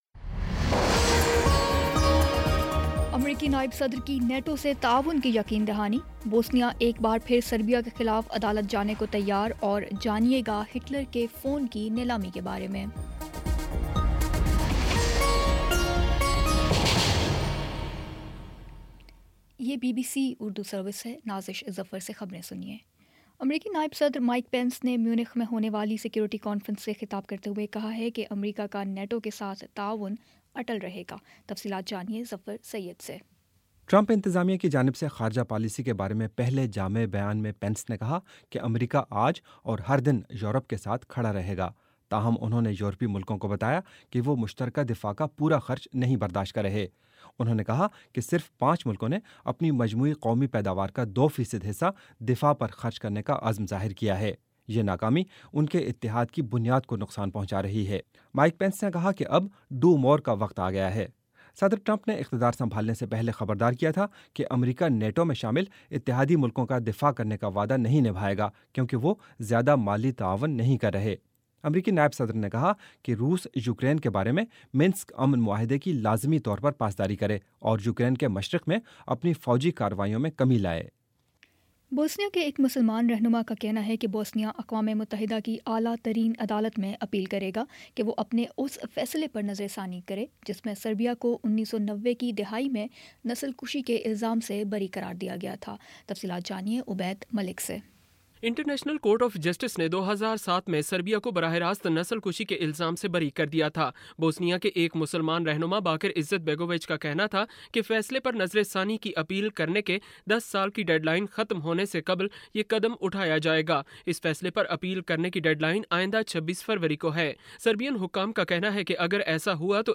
فروری 18 : شام چھ بجے کا نیوز بُلیٹن